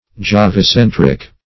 Search Result for " jovicentric" : The Collaborative International Dictionary of English v.0.48: Jovicentric \Jo`vi*cen"tric\, a. [See Jove , and Center .]
jovicentric.mp3